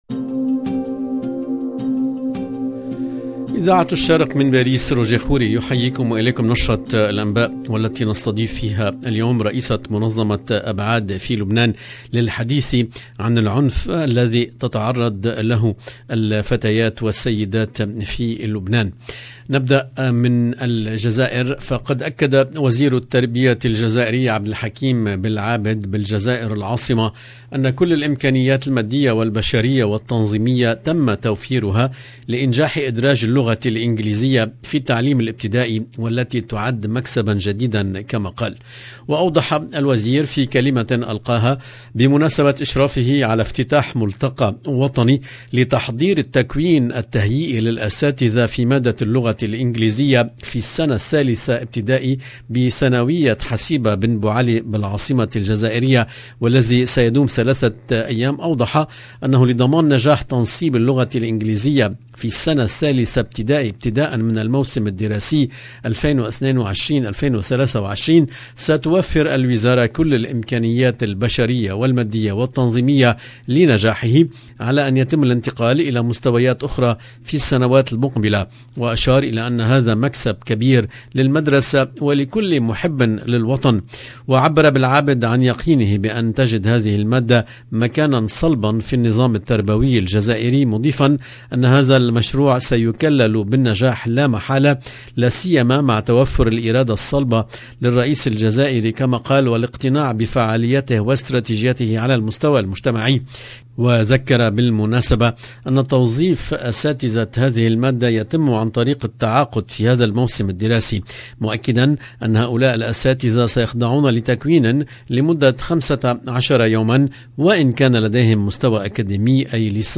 EDITION DU JOURNAL DU SOIR EN LANGUE ARABE DU 23/8/2022